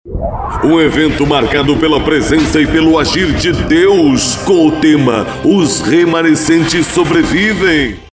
SPOT IGREJA FORTE:
Spot Comercial
Impacto
Animada